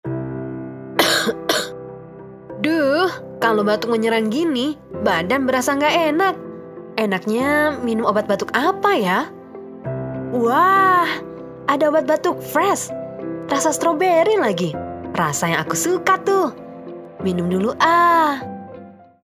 Need some Sweet , Relatable , Super Friendly , Smooth, Youthful, and Warm voice?
Casual Formal Indonesia JKB002_INDO_CASUAL 1 JKB002_INDO_CASUAL 2 JKB002_INDO_FORMAL